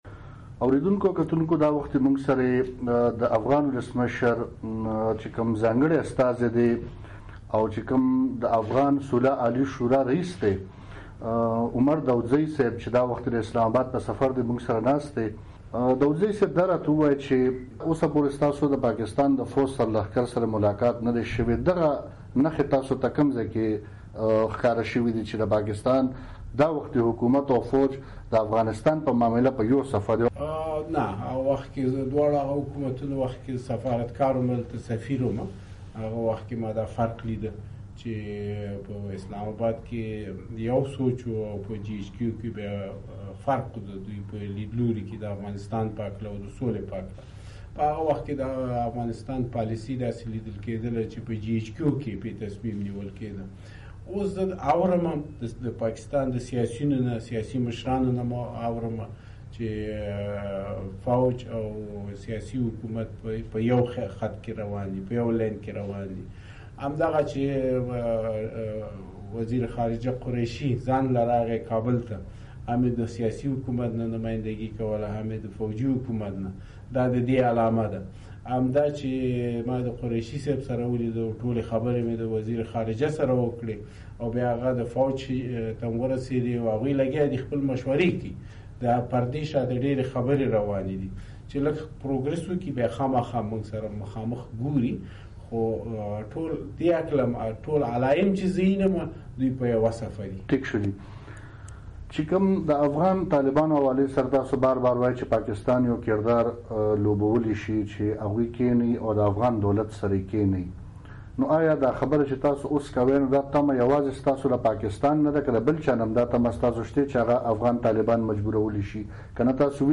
د افغان صدر اشرف غني عمر داودزي مرکه